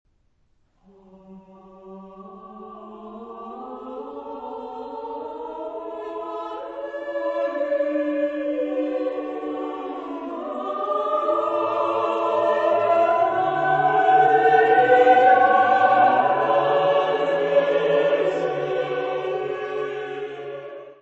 Music Category/Genre:  Classical Music
for four voices, a capella.